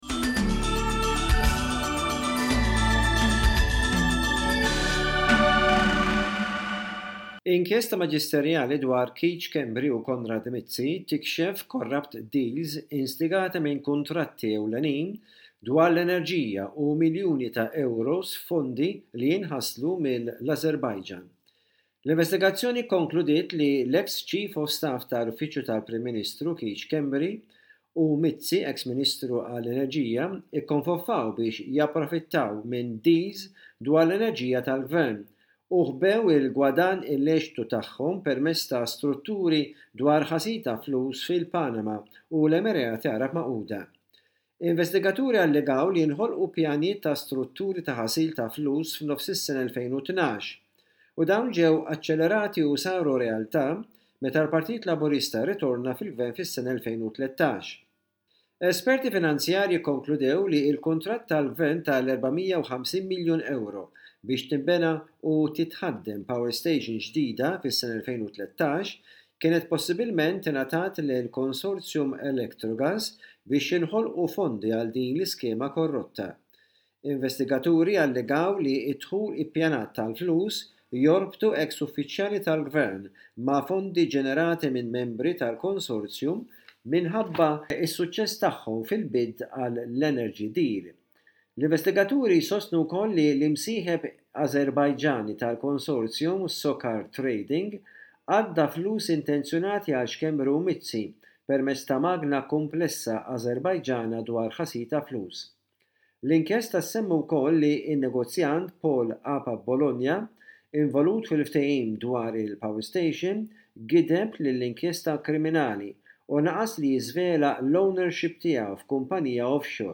Bullettin ta' aħbarijiet minn Malta